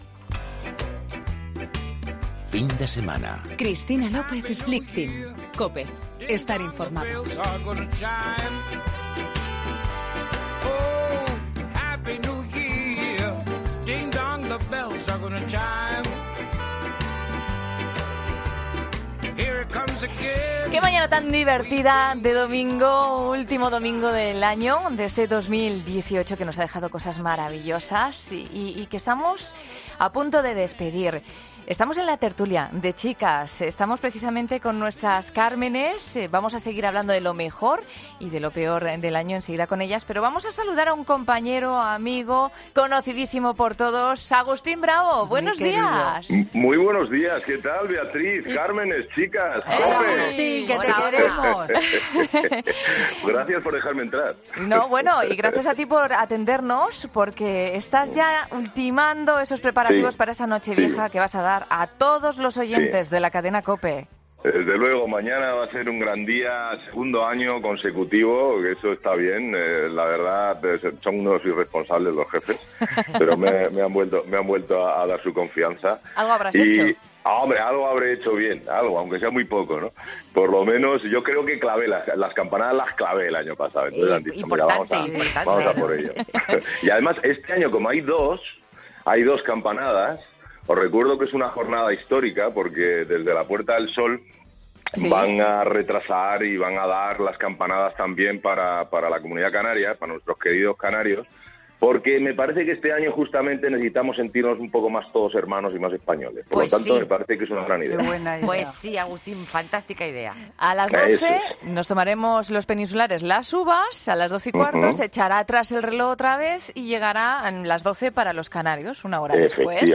Escucha la entrevista a Agustín Bravo en 'Fin de Semana'